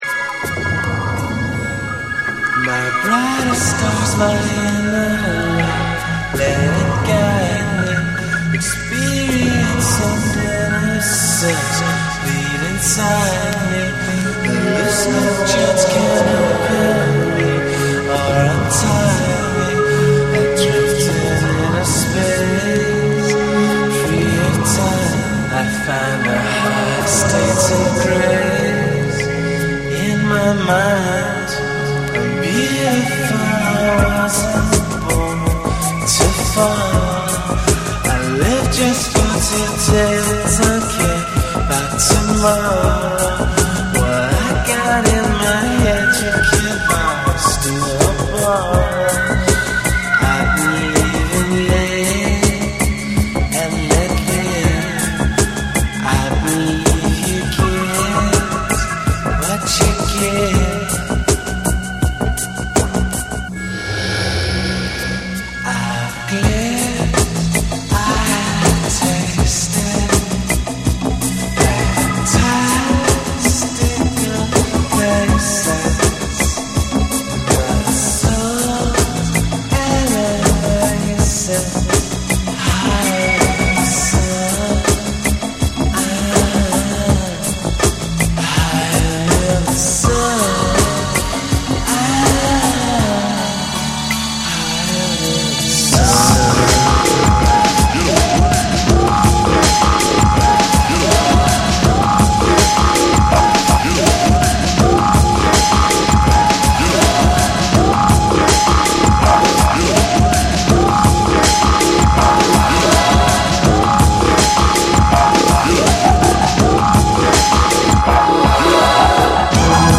TECHNO & HOUSE / NEW WAVE & ROCK